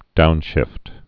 (dounshĭft)